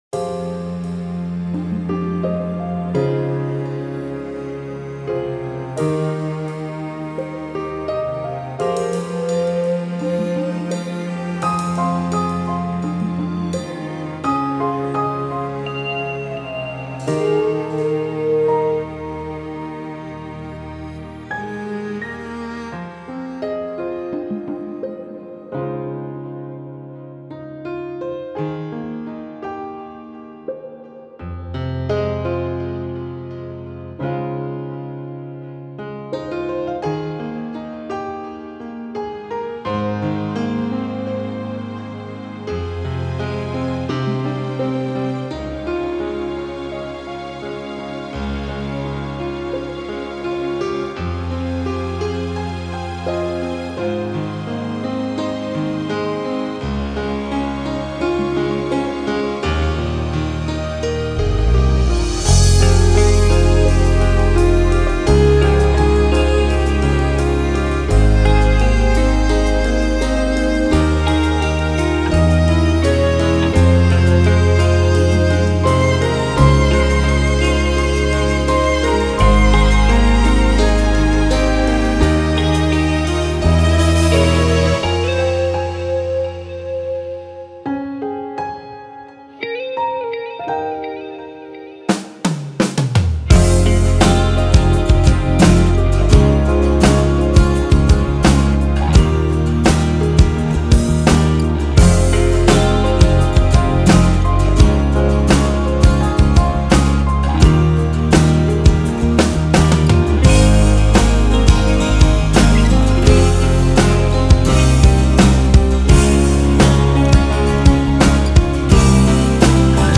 Сделал сведение и мастеринг минуса, что исправить мужики?